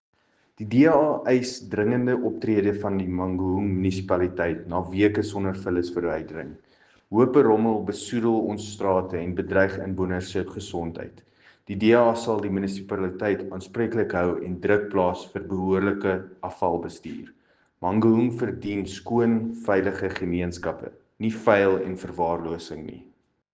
Afrikaans soundbites by Cllr Paul Kotzé and